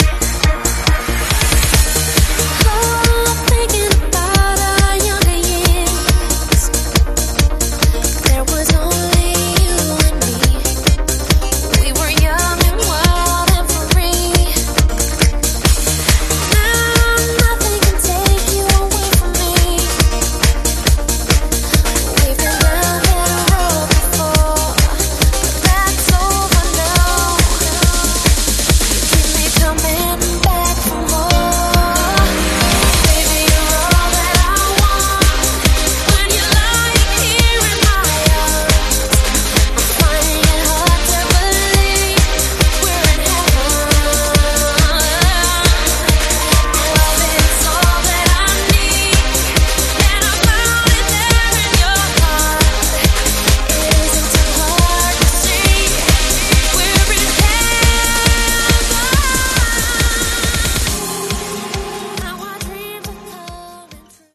Genre: 70's
Clean BPM: 122 Time